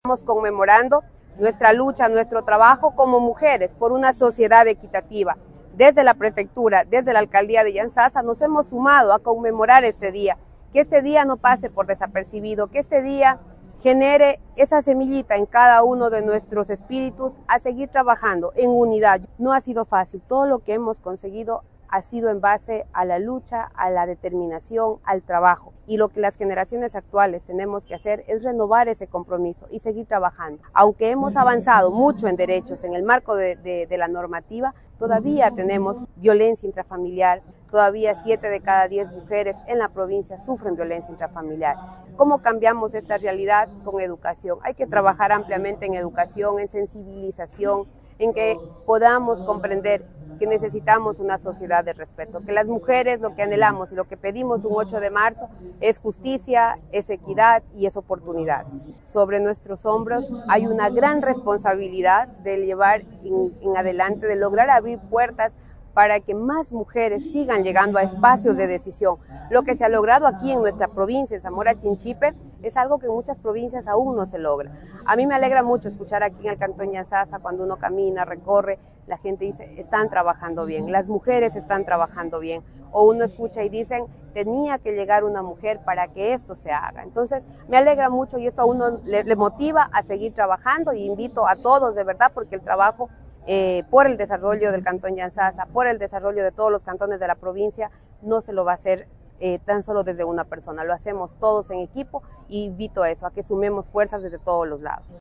Como parte de la agenda planificada por el Día Internacional de la Mujer, 8 de marzo, organizada por la prefecta de la provincia, Karla Reátegui y la alcaldesa de Yantzaza, María Lalangui, se efectuó con éxito el Encuentro y Feria Provincial de Mujeres Lideresas en el Valle de las Luciérnagas.
KARLA REÁTEGUI, PREFECTA